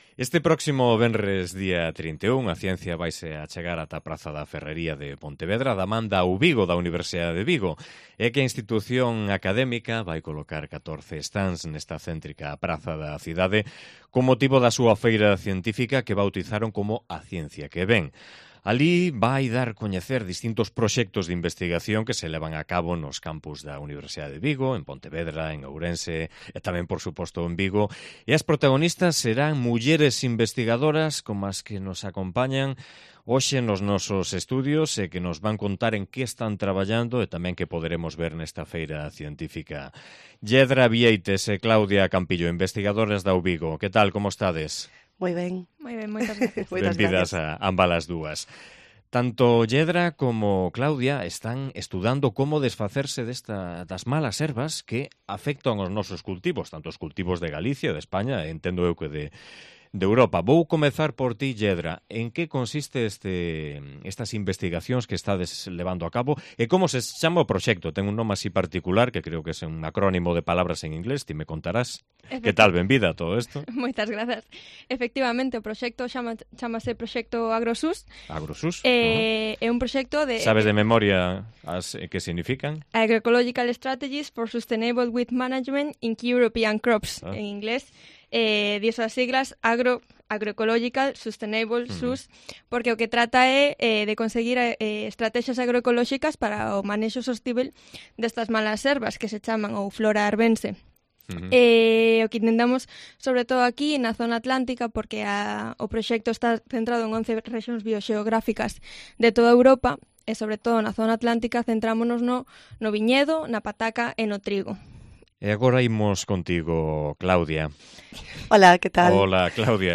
Allí dará a conocer distintos proyectos de investigación que se llevan a cabo en los campus de la Uvigo y las protagonistas serán mujeres investigadoras como las que nos acompañaron este martes en nuestros estudios y que nos contaron en qué están trabajando y qué podremos ver en esta feria científica que se celebra este viernes en la ciudad del Lérez.